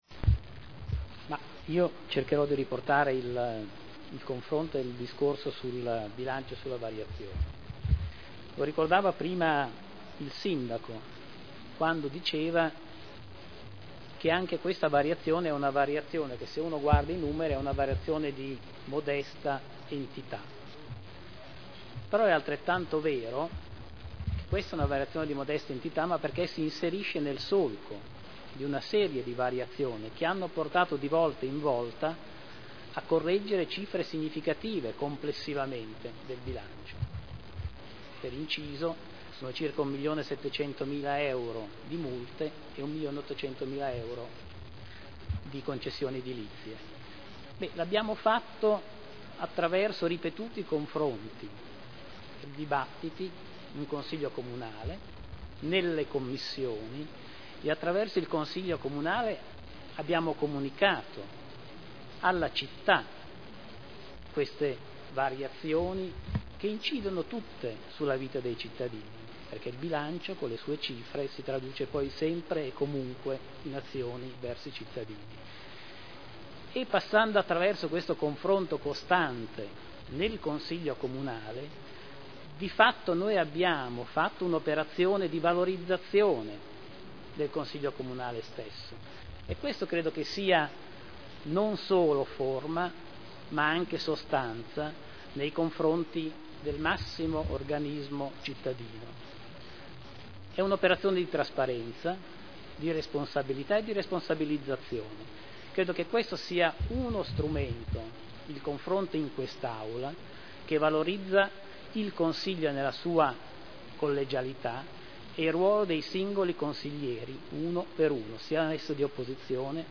Seduta del 22/11/2010 Bilancio di Previsione 2010 - Bilancio Pluriennale 2010/2012 - Programma triennale dei Lavori Pubblici 2010/2012 - Assestamento - Variazione di Bilancio n. 3 - Replica -